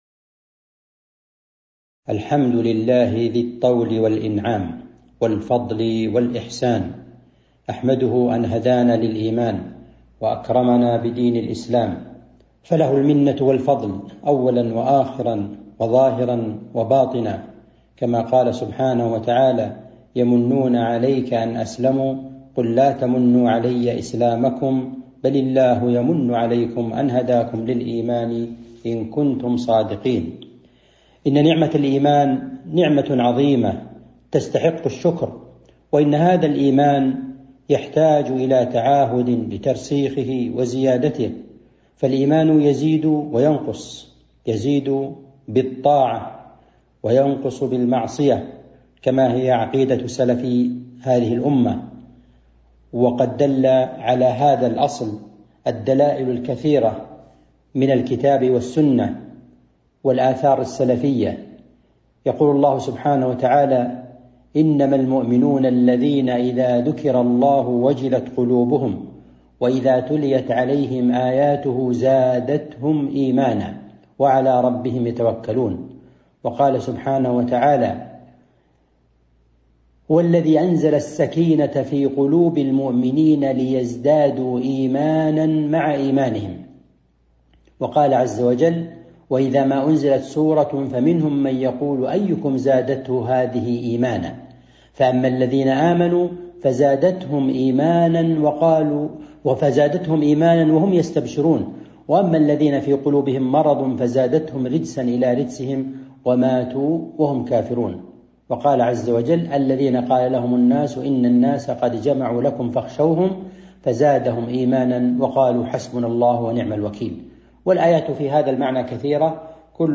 تاريخ النشر ٢٤ ذو الحجة ١٤٤٣ هـ المكان: المسجد النبوي الشيخ